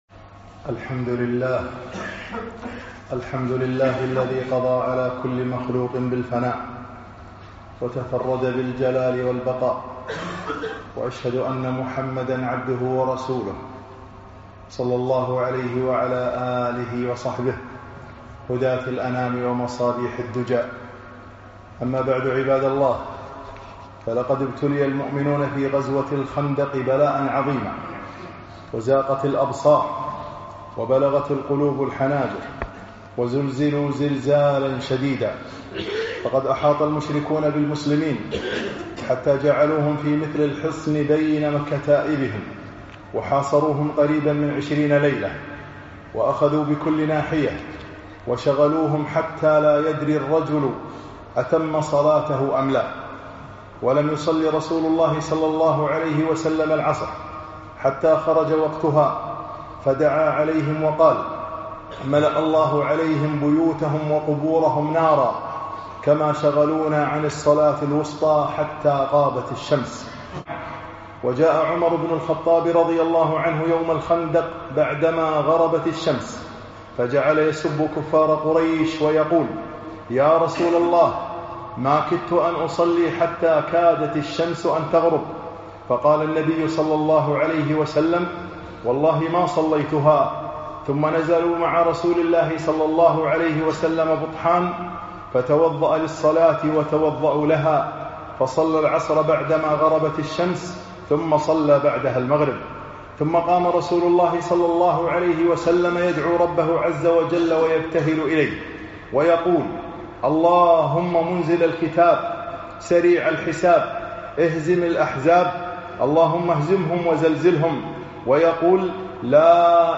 خطب السيرة النبوية 18